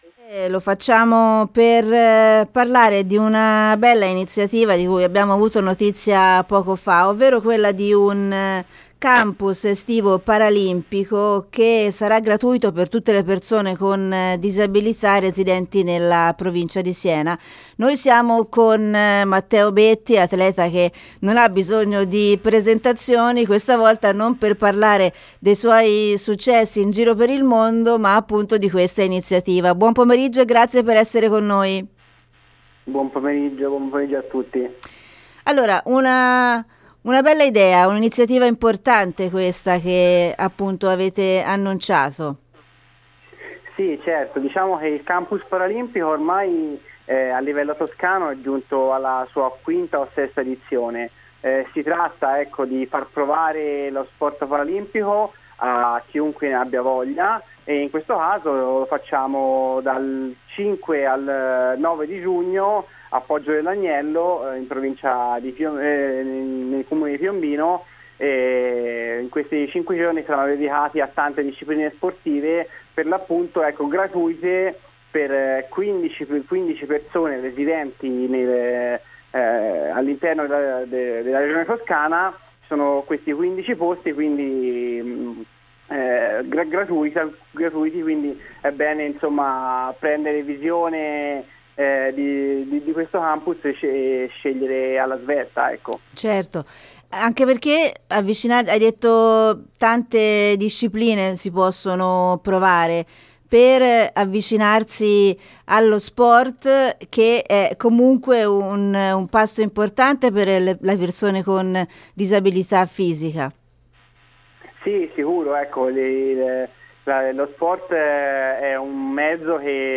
Ascolta l’intervista di Matteo Betti, campione paralimpico e delegato provinciale Cip Siena